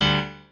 piano9_17.ogg